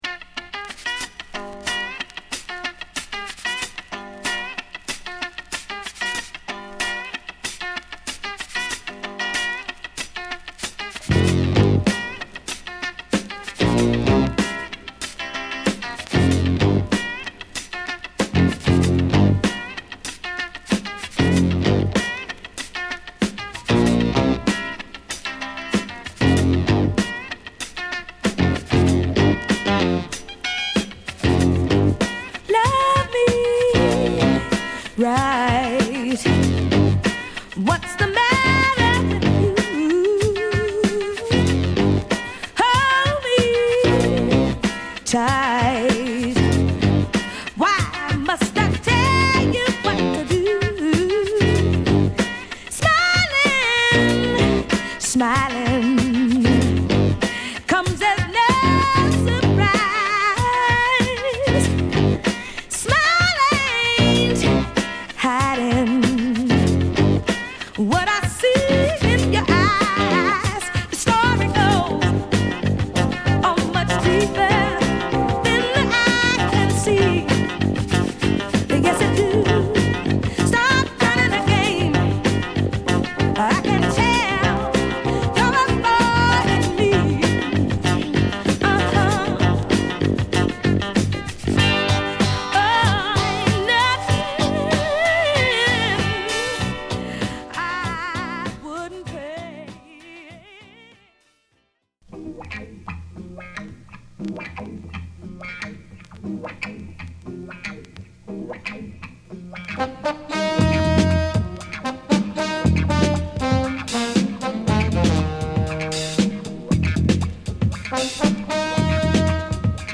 US